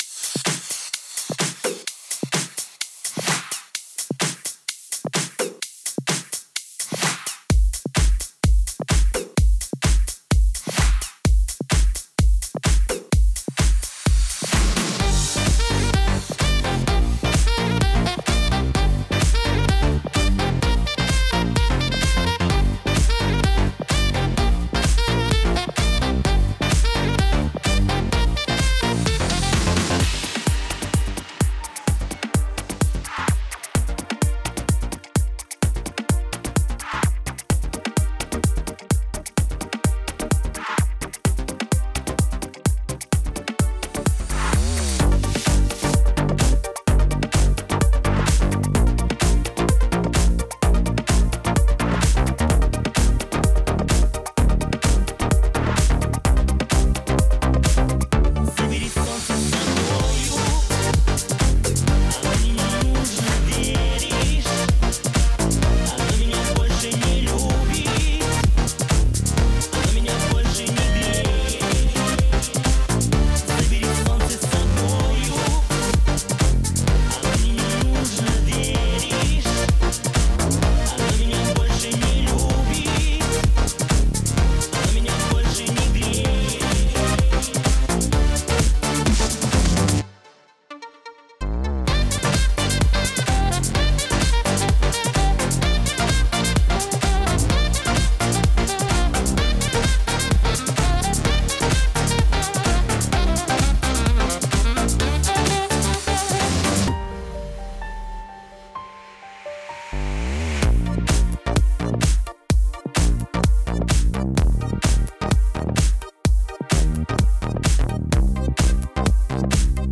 минусовка версия 17119